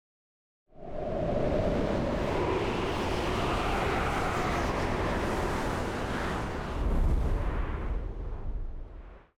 SFX_Schlappentornado_06.wav